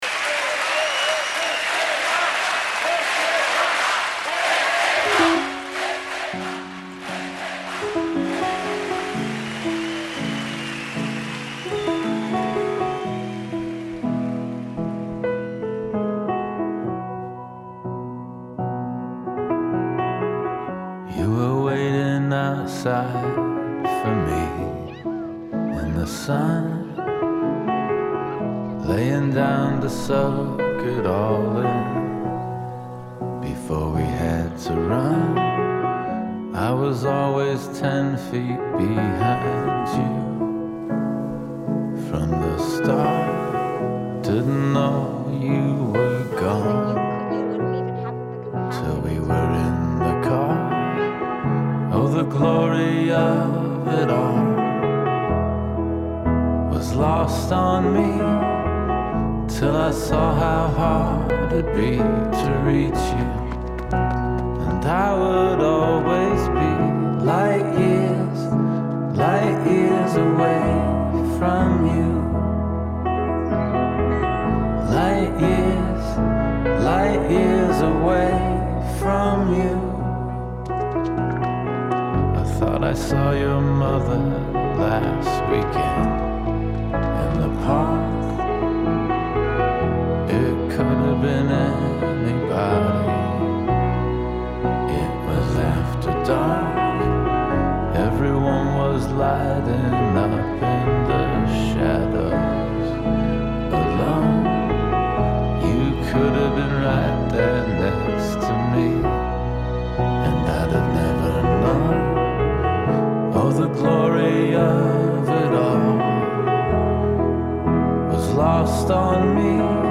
Naiz irratiko saio musikala. Euskal Herriko musikariek txandaka gidatutako saioa.